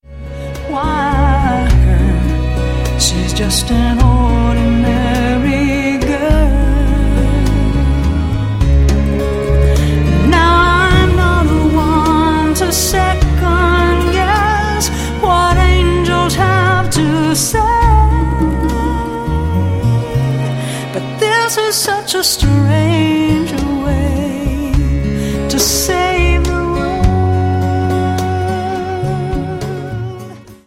• Sachgebiet: Pop